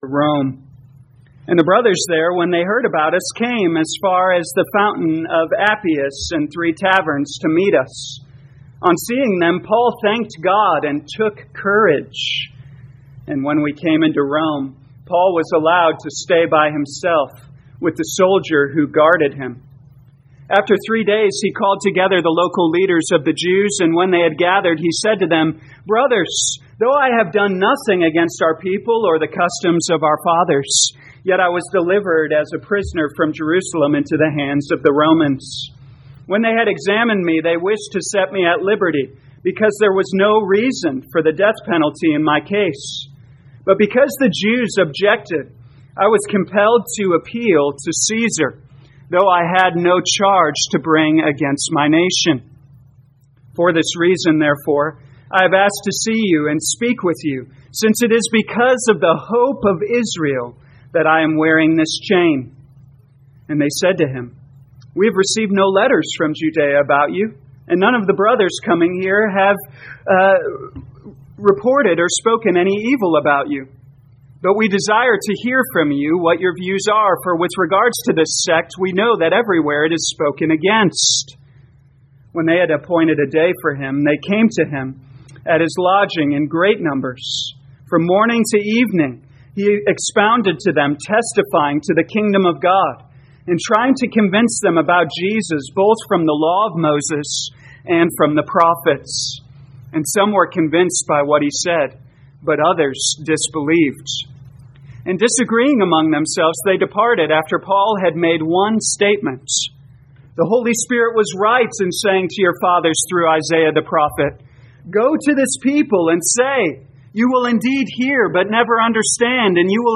2022 Acts Morning Service Download